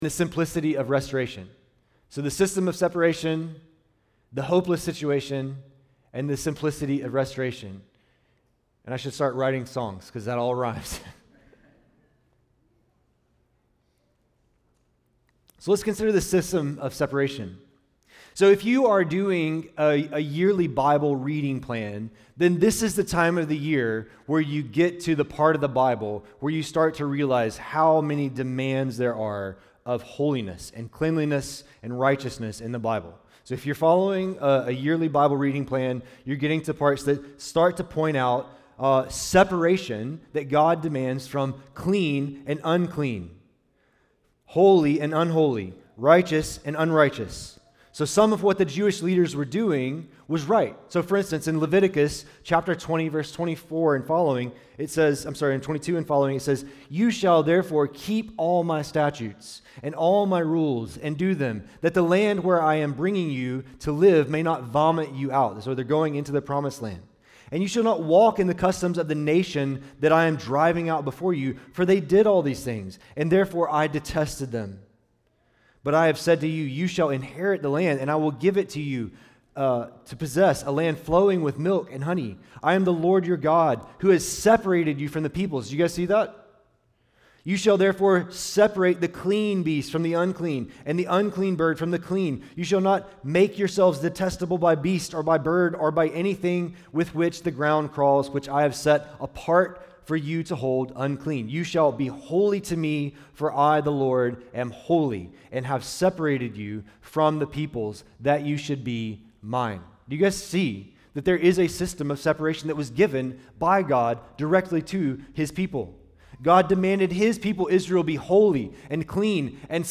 Kahului Baptist Church Sermons | Kahului Baptist Church